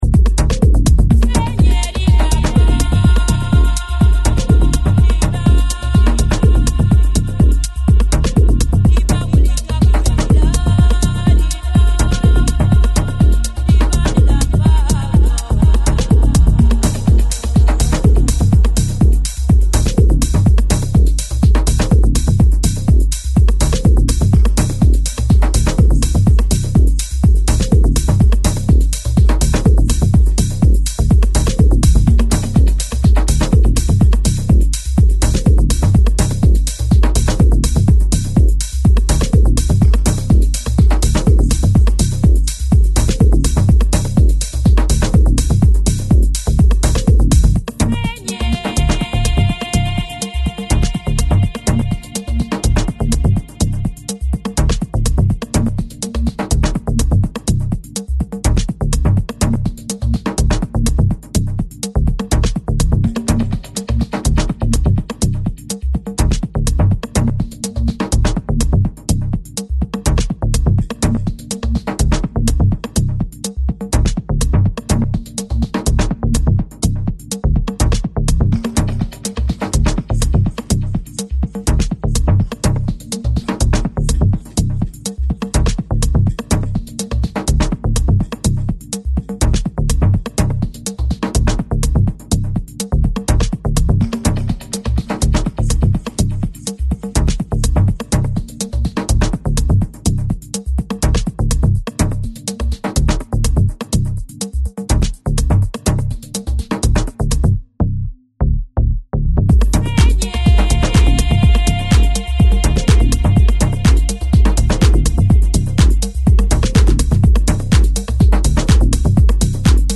tribal-drums contaminations